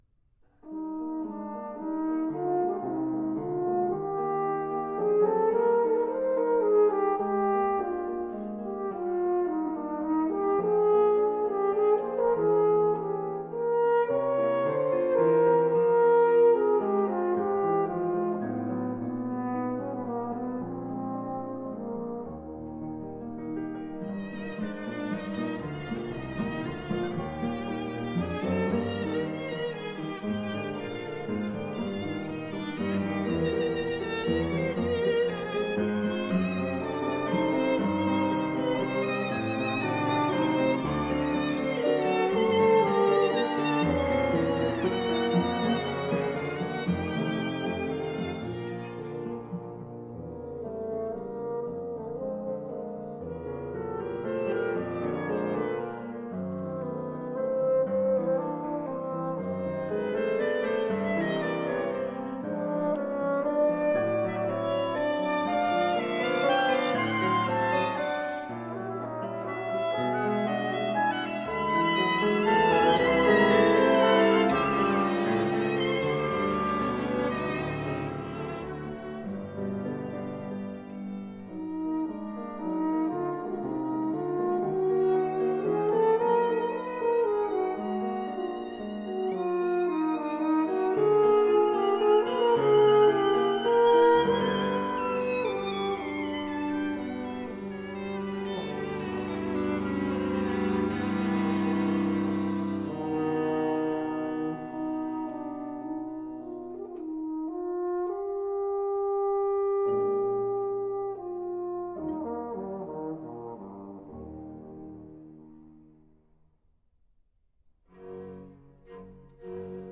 Soundbite 3rd Movt